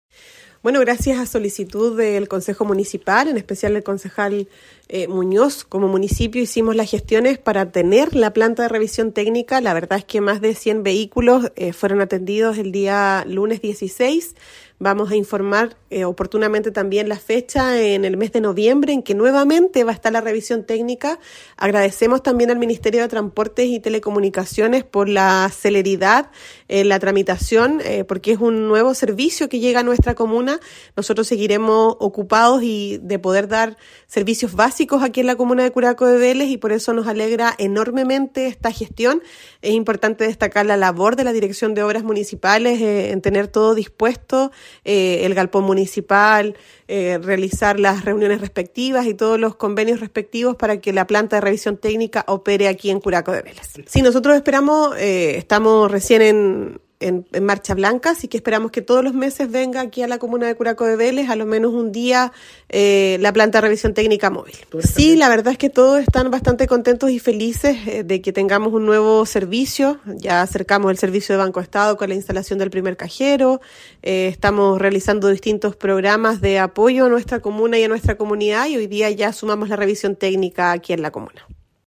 Al respecto, la jefa comunal Javiera Yáñez destacó: